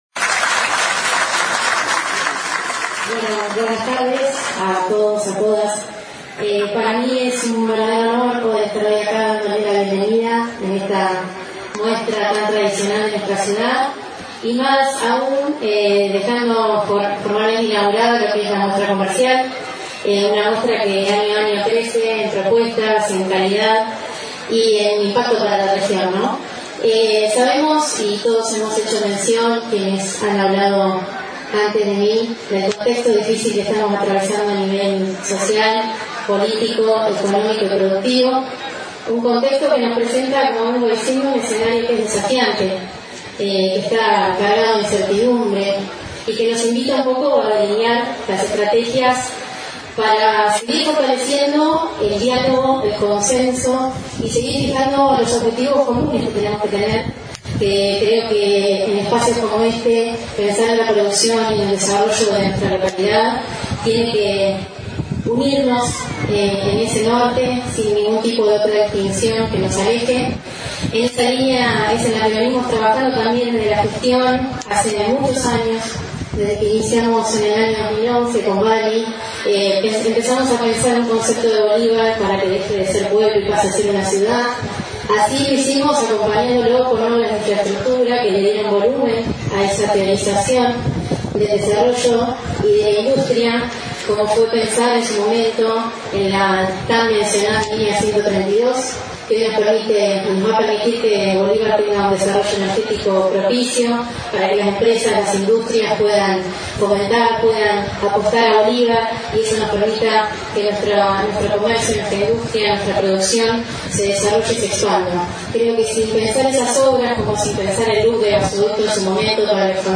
Se Inauguró El Sector Comercial En La Exposición Rural de Bolívar 2025
Discursos:
Laura Rodriguez, Intendente Interina - AUDIO